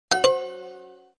bad sound.mp3